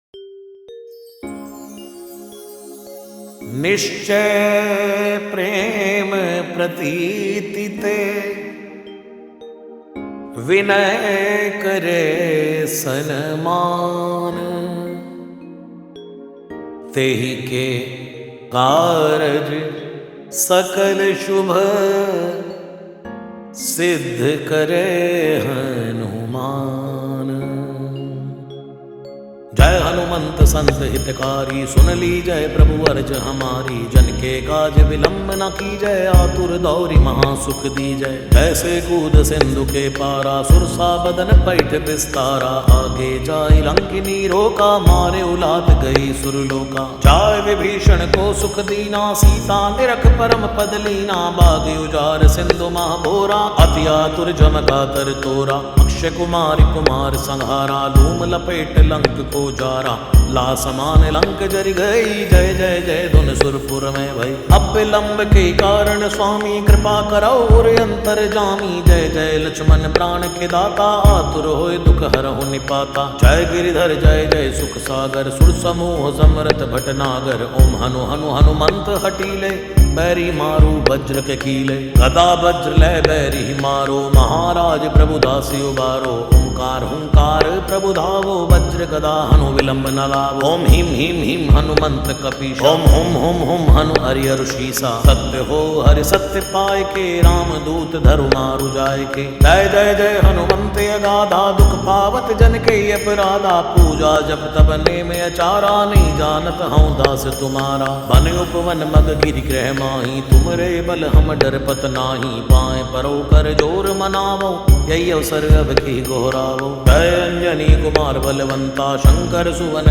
Releted Files Of Bhakti Lofi Mp3 Song